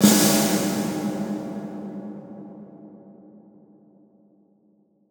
Big Drum Hit 17.wav